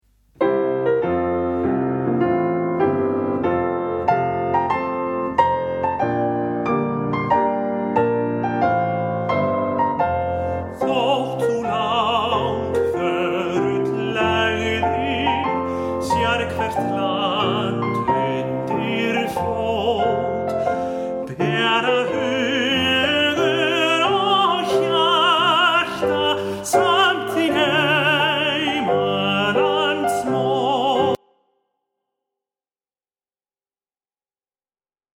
leikur á píanó